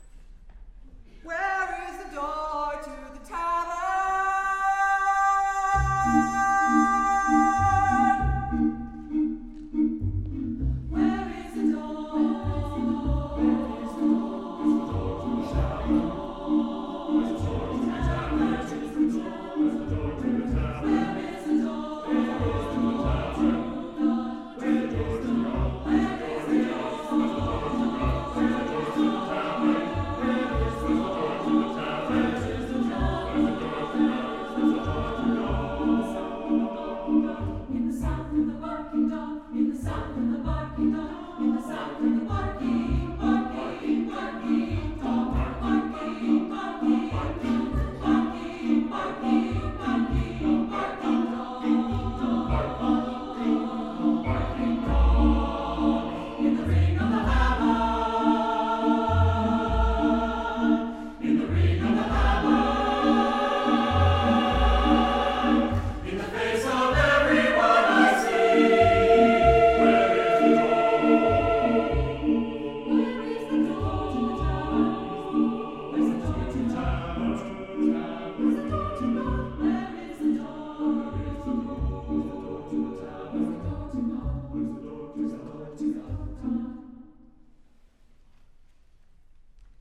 is a rowdy, polyphonic celebration